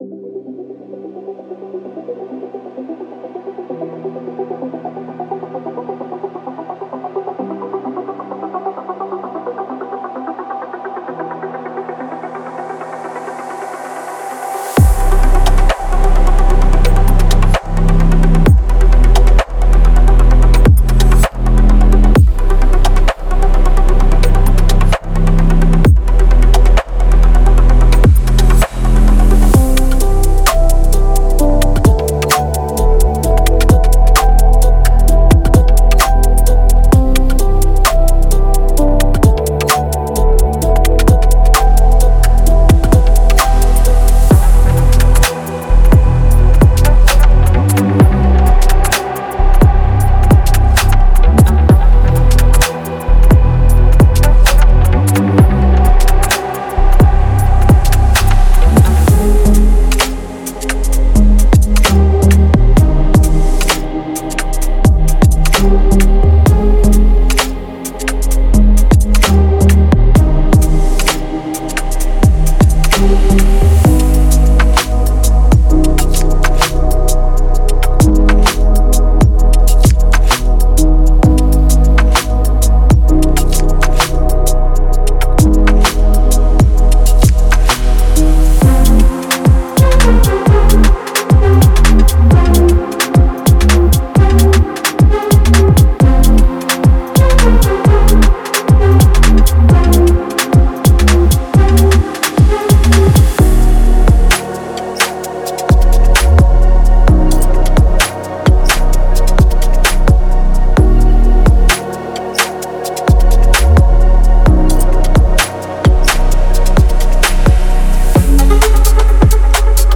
Future Garage